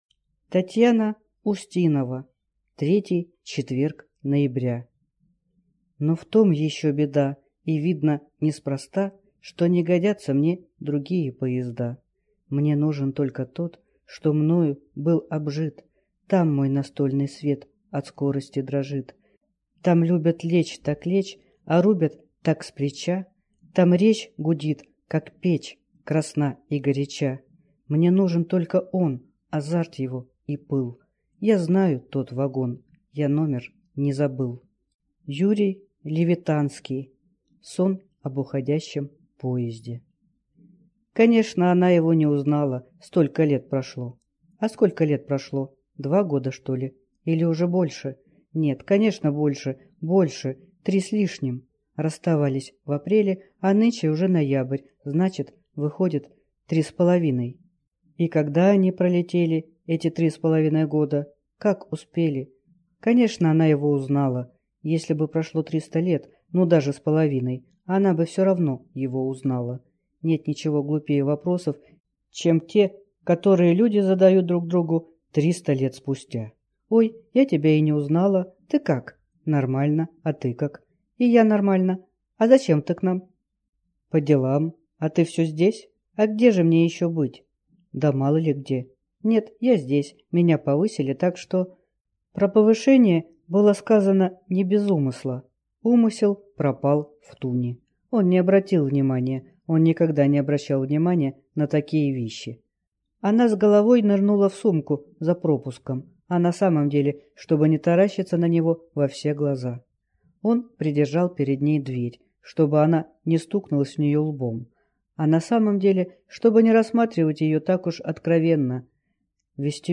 Аудиокнига Третий четверг ноября | Библиотека аудиокниг